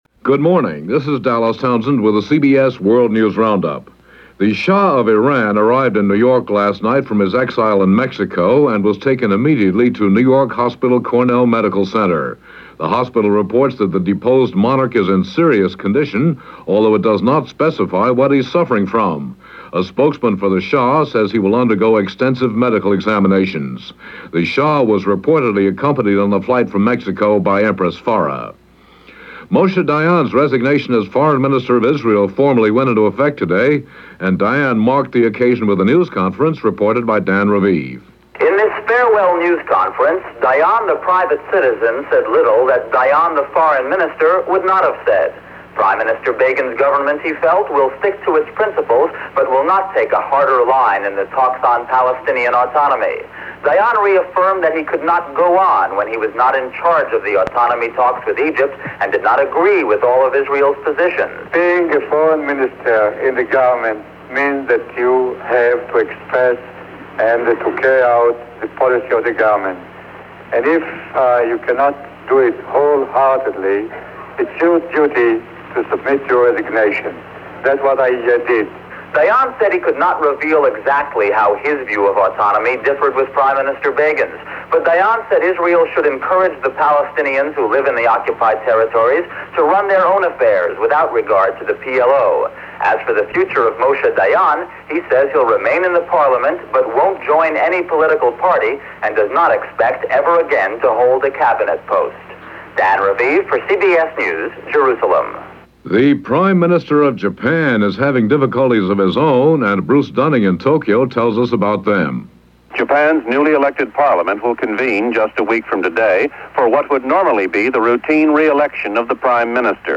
That’s how it went, Iran, the Shah and a whole lot more this October 23rd, 1979 as reported by the CBS World News Roundup.